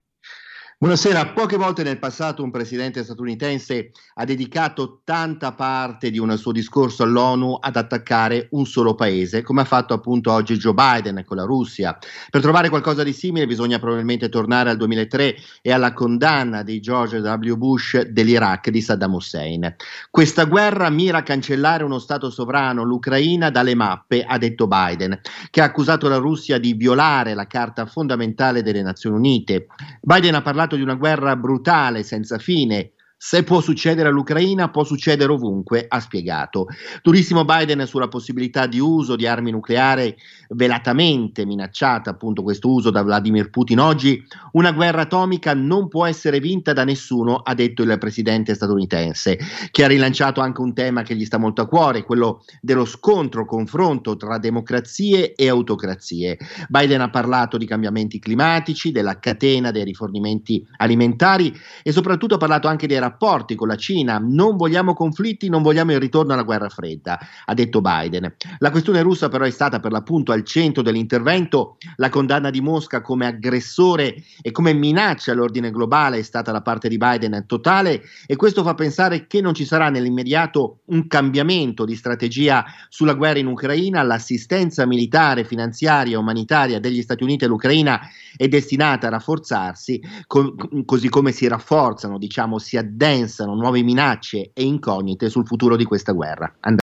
Nel pomeriggio l’intervento del presidente Biden all’assemblea delle Nazioni Unite. In diretta